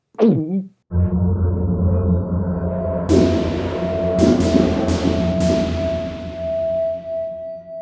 drink potion sound.
potion.wav